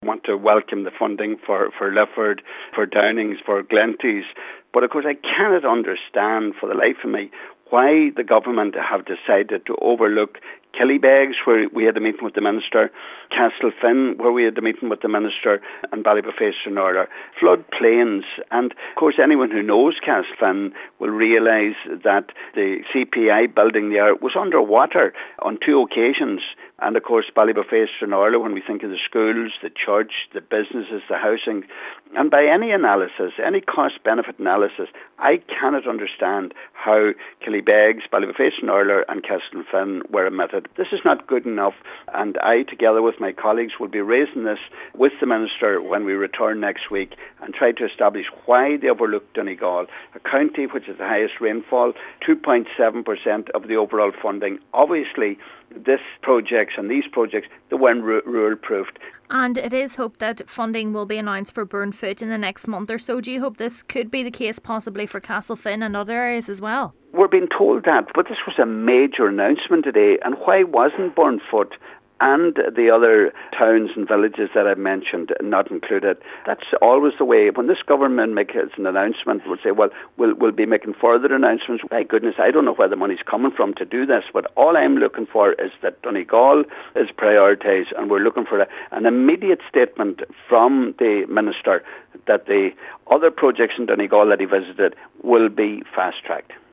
Deputy Gallagher says he will be challenging the Minister next week as to why other flood risk areas of the county were omitted in this funding announcement: